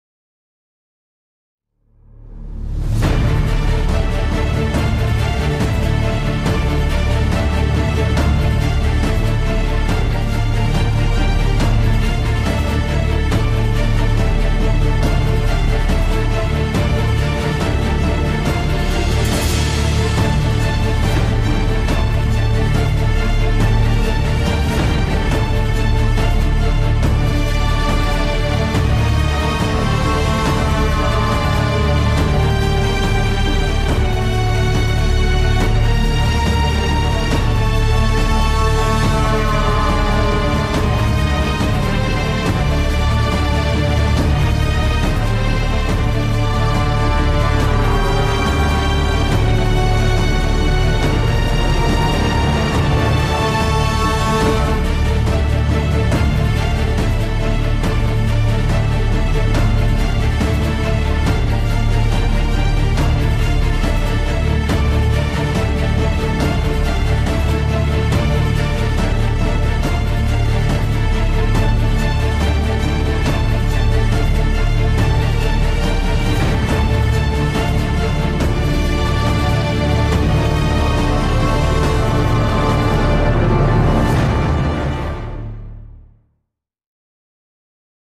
tema dizi müziği